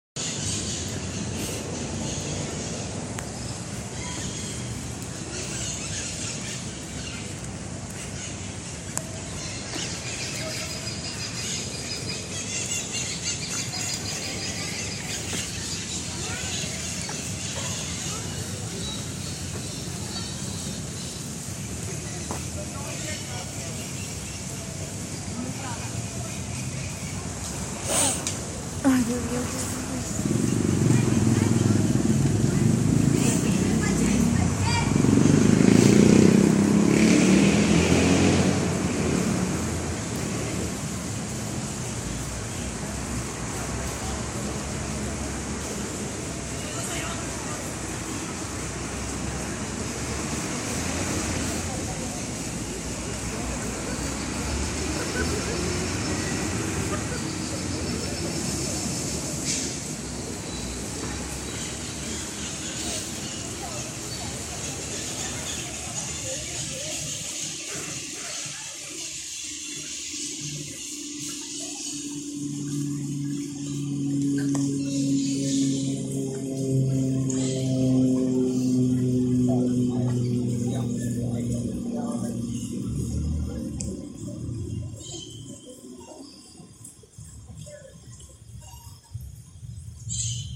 Encuentro entre la ciudad y la naturaleza. ALAJUELA